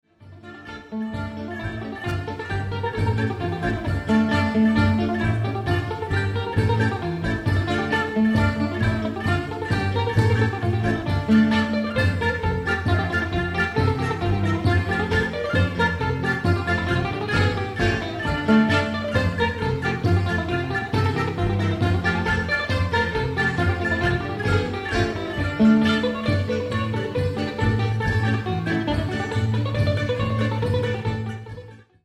Medium-to-fast tempo Hasapikos
The faster part at the end is a very old folk melody.